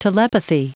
Prononciation du mot : telepathy
telepathy.wav